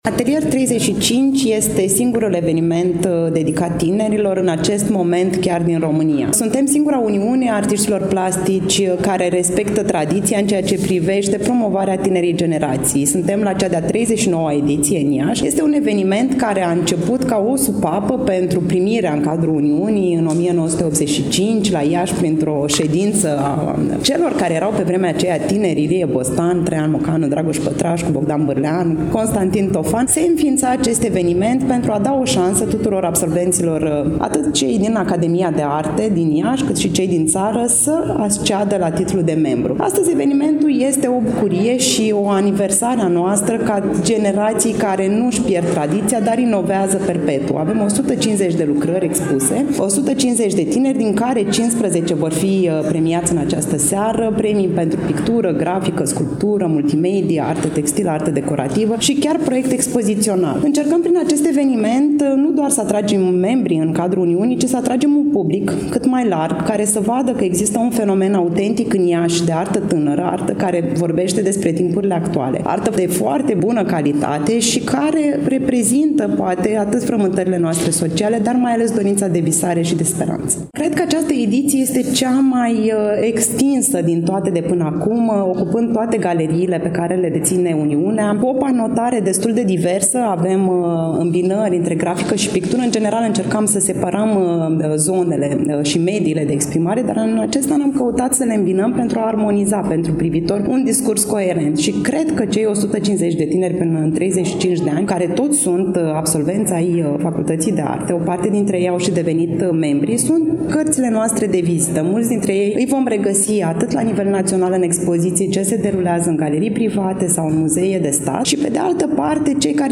Evenimentul s-a desfășurat în ziua de vineri, 5 aprilie 2024, începând cu ora 17 și 30 de minute, în incinta Galeriei de Artă „Th. Pallady” de pe strada Alexandru Lăpușneanu, Numerele 7-9, târgul Iașilor.